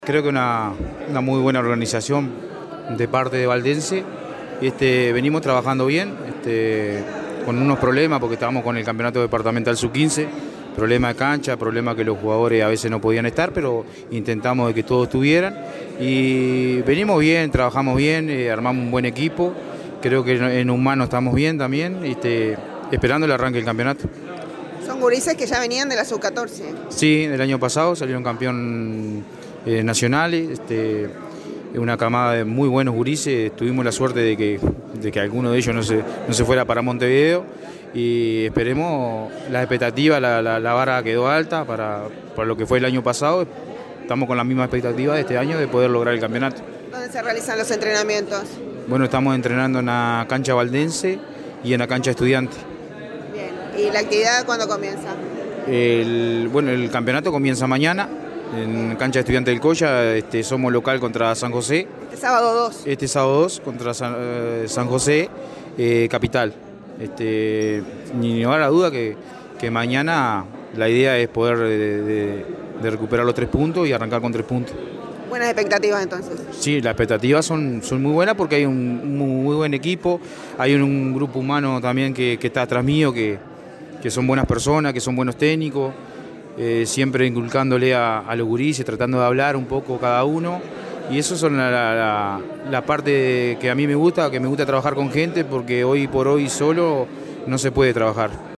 presente en la noche del viernes en el lanzamiento del combinado que organiza el club Atlético Valdense…